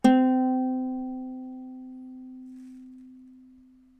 Instrument Sounds
What is a Ukulele
uke2.wav